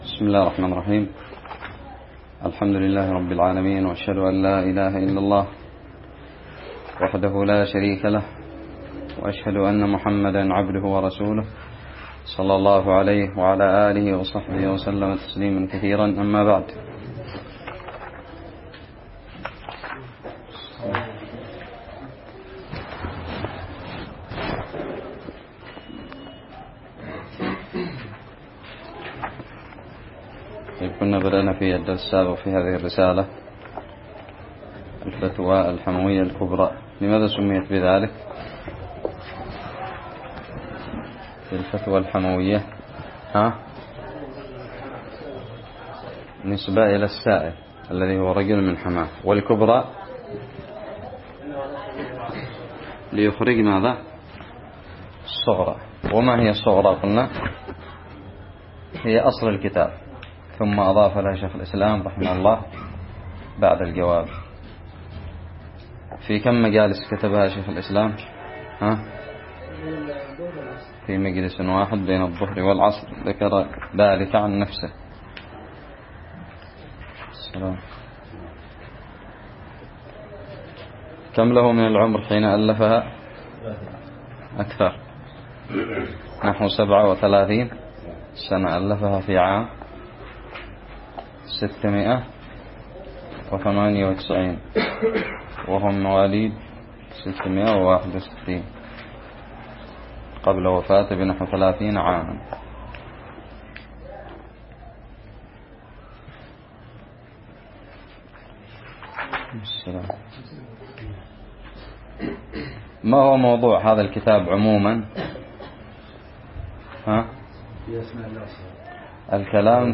الدرس الثاني من شرح متن الحموية
ألقيت بدارالحديث السلفية للعلوم الشرعية بالضالع